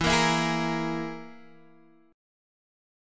EMb5 chord {0 1 2 1 x x} chord